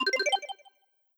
Success5.wav